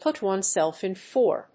アクセントpùt onesèlf ín for…